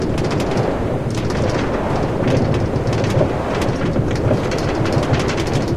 minecart
inside.mp3